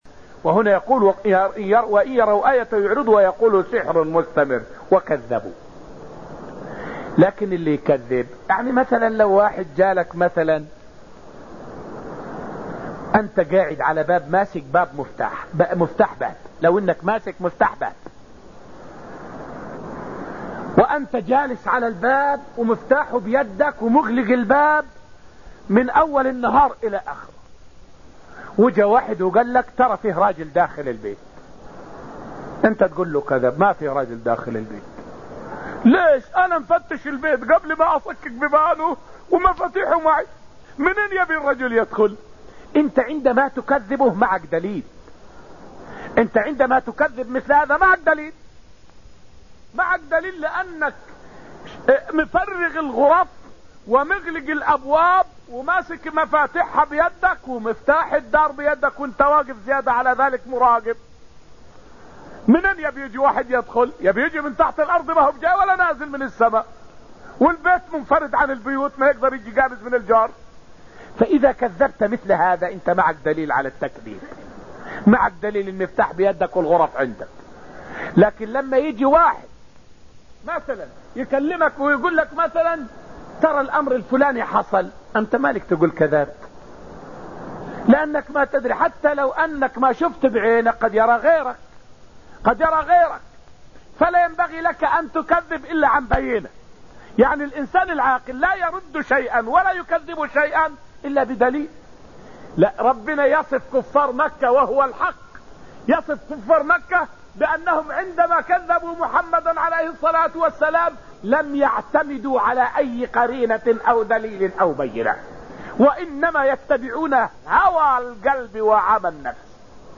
فائدة من الدرس الرابع عشر من دروس تفسير سورة البقرة والتي ألقيت في المسجد النبوي الشريف حول أحكام للتعامل مع أهل الأهواء.